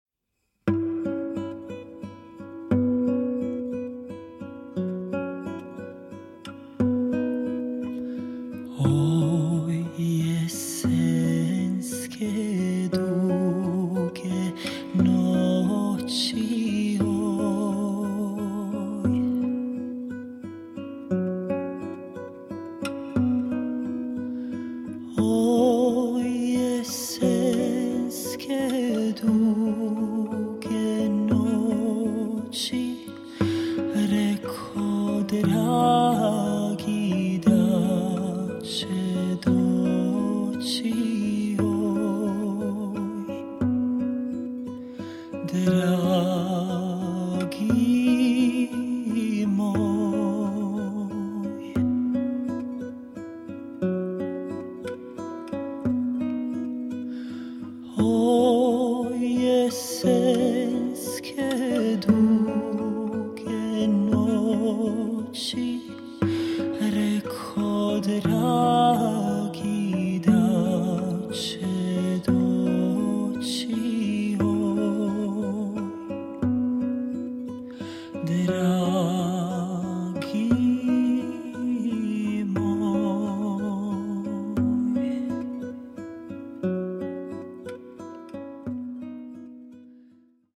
Voice
Guitar